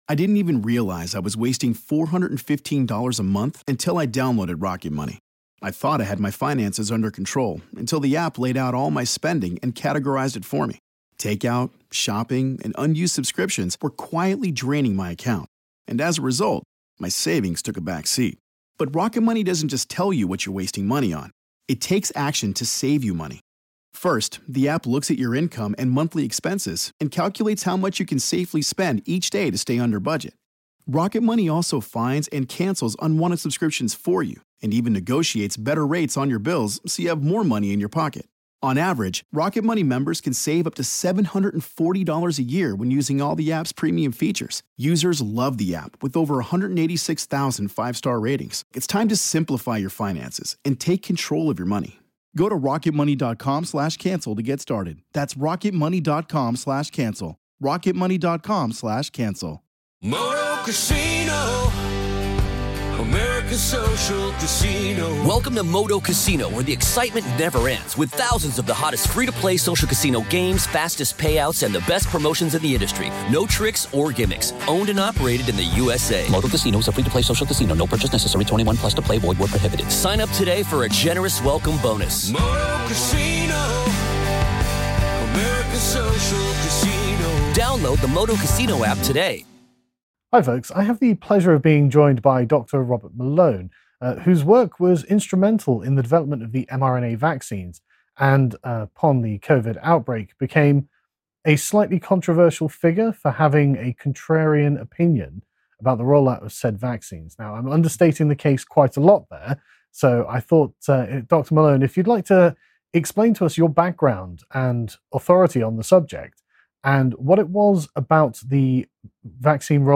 Interview with Dr. Robert Malone